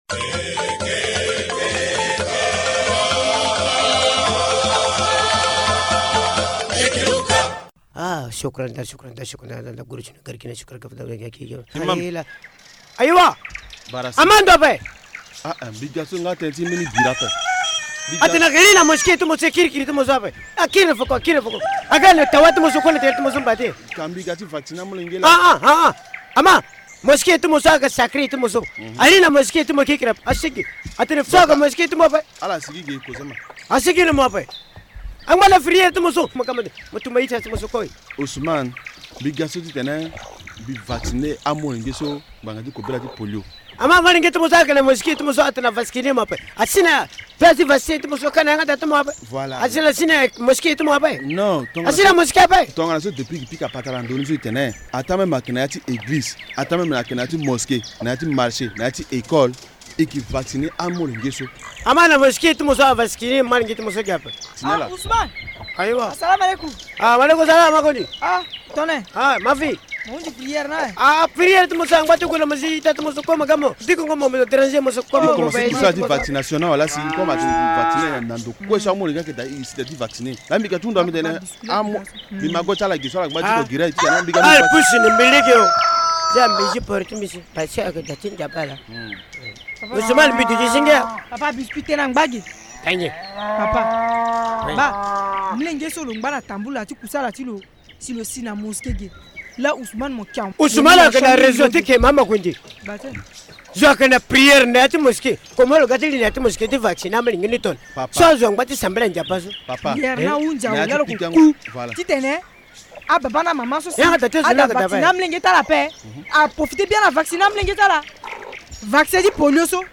La campagne de vaccination contre la poliomyélite démarre vendredi 4 octobre 2019. Les comédiens invitent la population a bien accueillir les agents vaccinateurs.